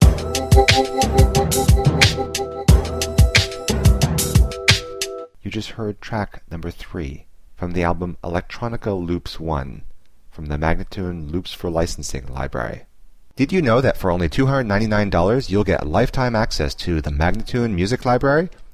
Instrumental samples in many genres.
090-C-ambient:teknology-1026